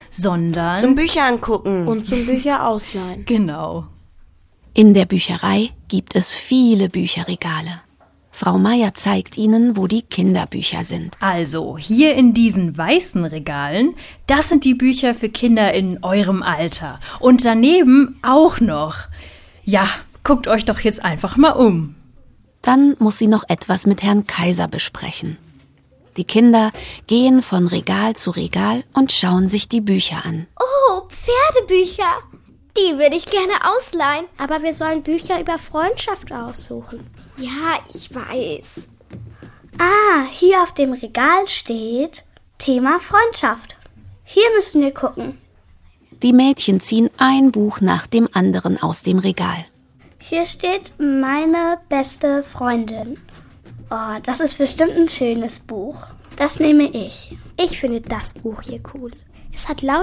Hörspiele